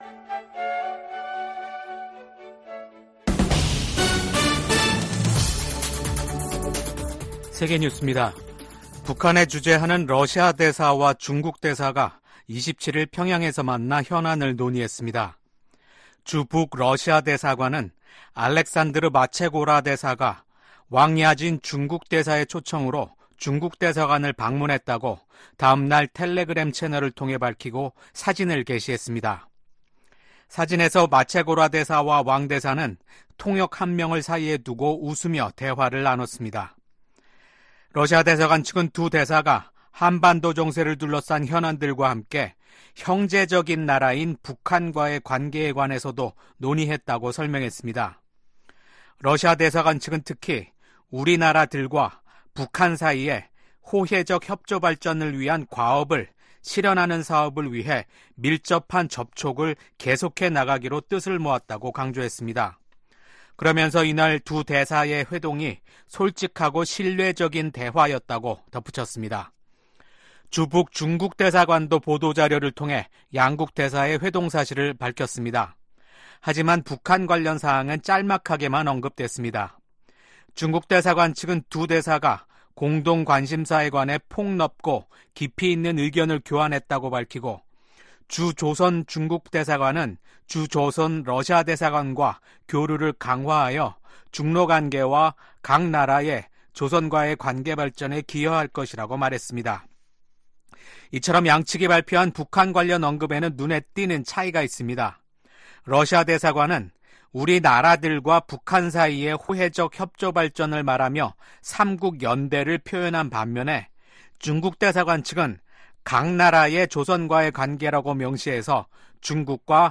VOA 한국어 아침 뉴스 프로그램 '워싱턴 뉴스 광장' 2024년 6월 29일 방송입니다. 조 바이든 대통령과 도널드 트럼프 전 대통령이 첫 대선 후보 토론회에 참석해 날선 공방을 벌였습니다. 미국 정부는 한국 정치권에서 자체 핵무장론이 제기된 데 대해 현재 한국과 공동으로 확장억제를 강화하고 있다고 강조했습니다. 미 국무부 고위 관리가 최근 심화되고 있는 북한과 러시아 간 협력에 대한 중대한 우려를 나타냈습니다.